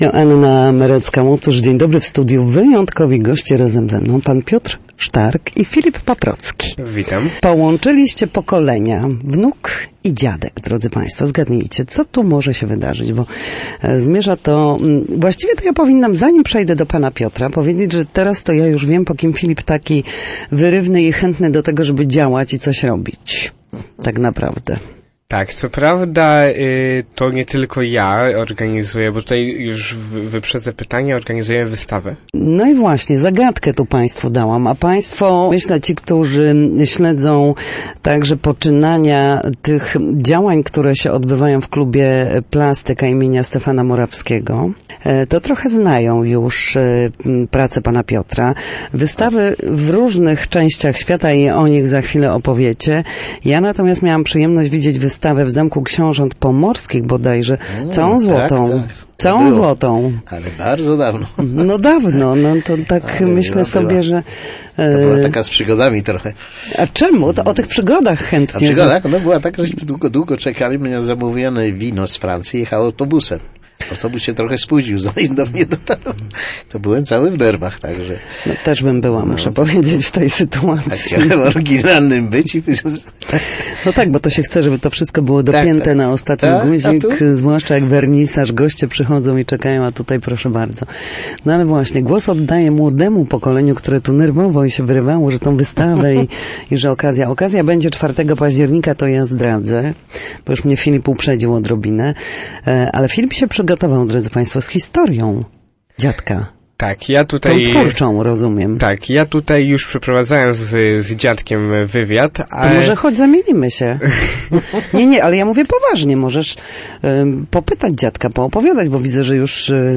Studio Słupsk Radia Gdańsk